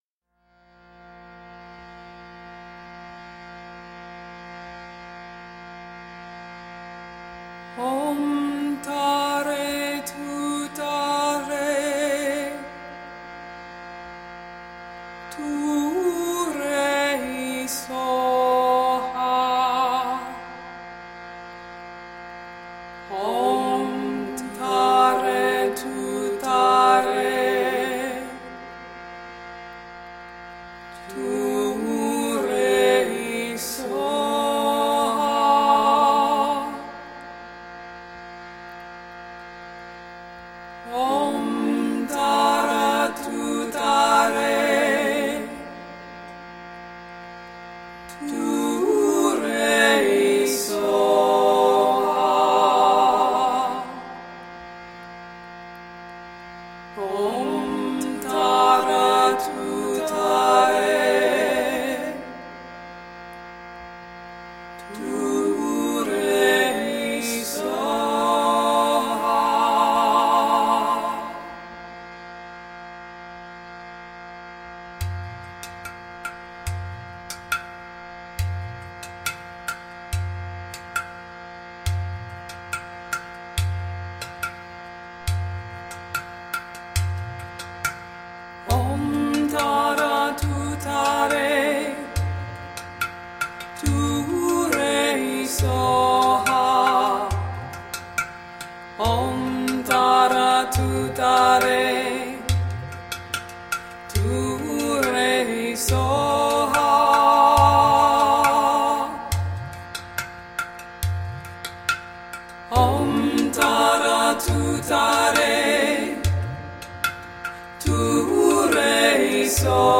Tagged as: World, New Age, Indian Influenced